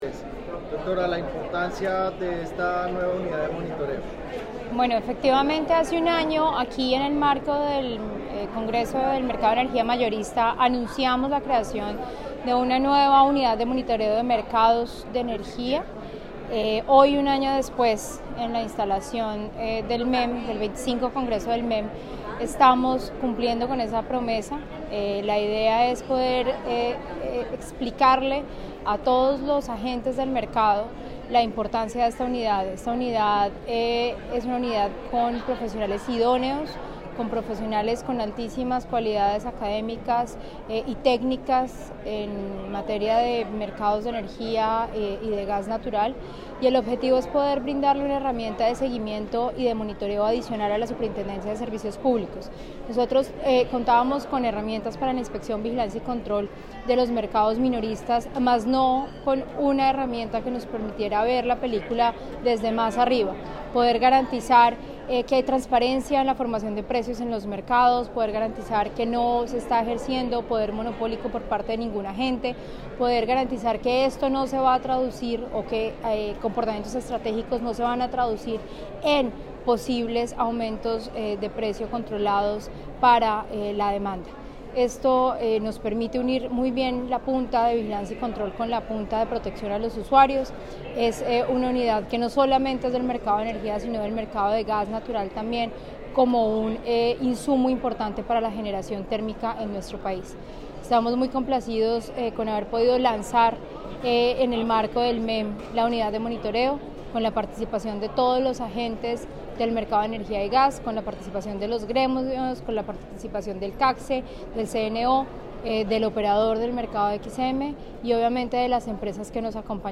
Declaraciones superintendente sobre objetivos de la Unidad de monitoreo
declaraciones_super_natasha_avendano-30-oct-19.mp3